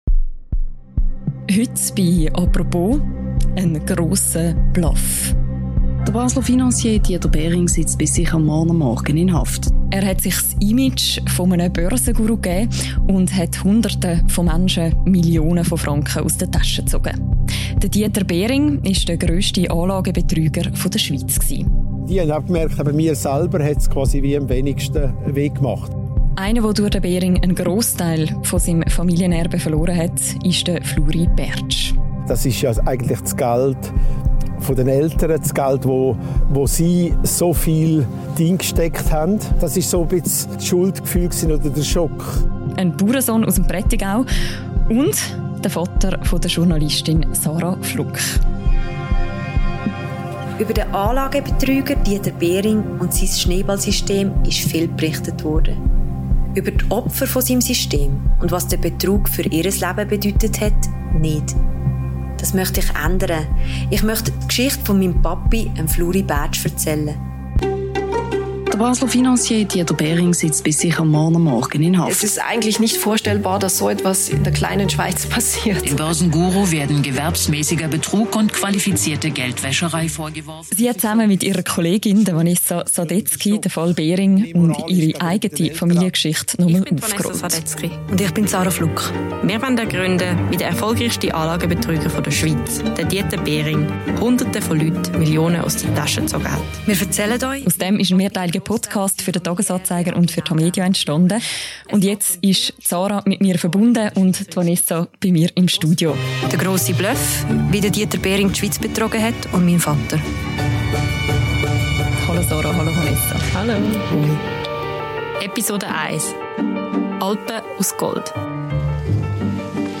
Sie sind zu Gast in dieser Episode «Apropos» und erzählen über den Fall Dieter Behring und wie die Podcast Serie zu Stande gekommen ist.